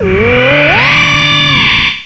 cry_not_aurorus.aif